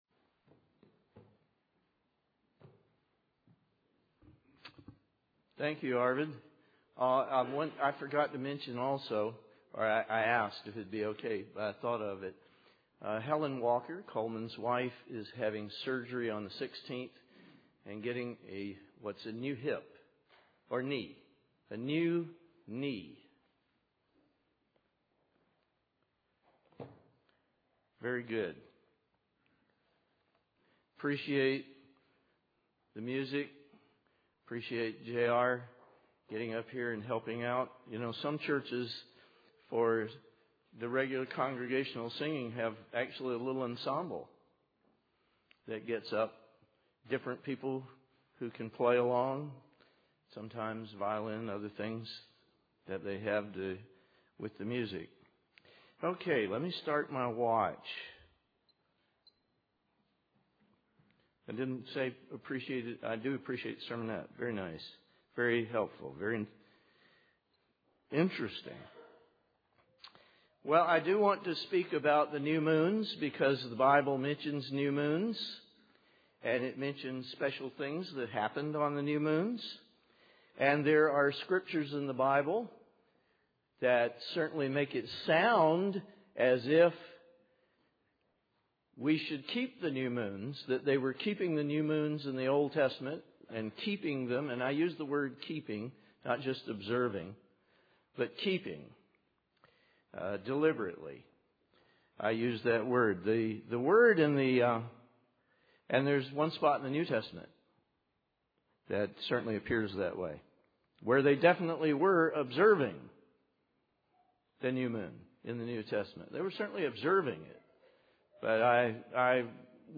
Given in Nashville, TN
Related Reading; United Church of God Study Paper UCG Sermon Studying the bible?